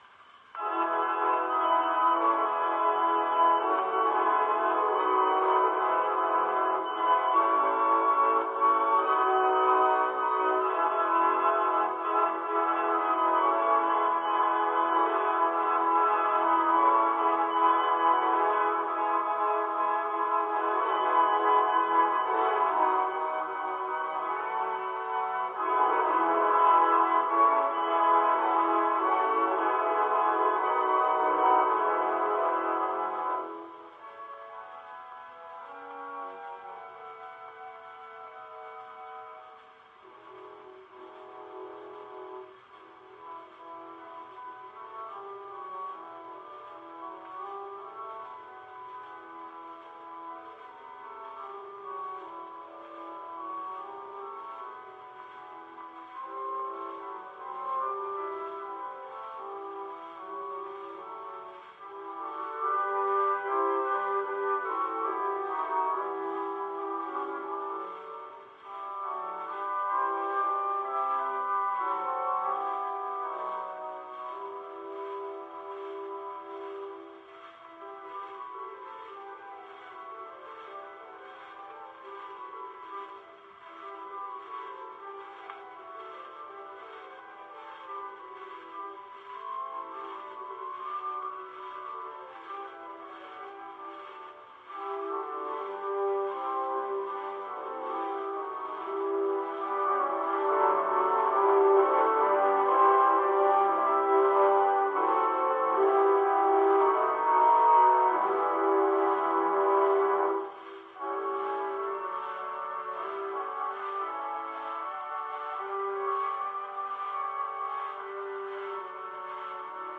Pipe Organ Solo